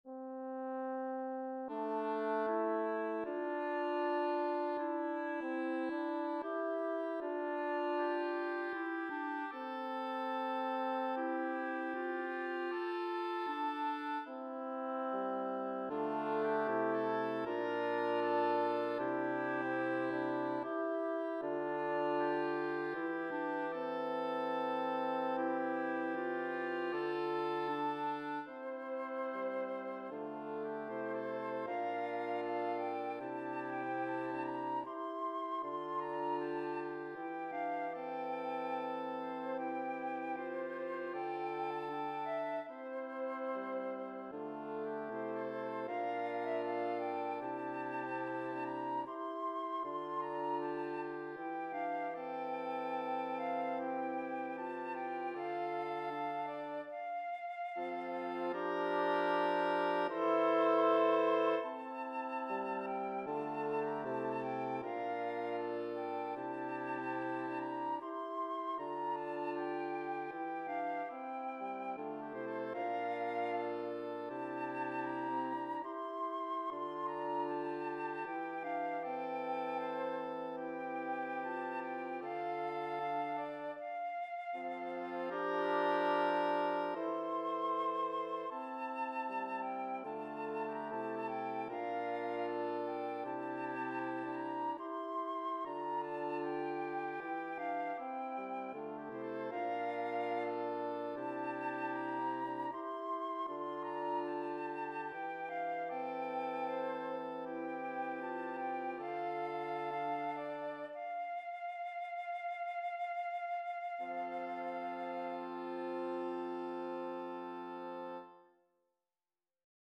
Voicing/Instrumentation: Bassoon , Clarinet , Oboe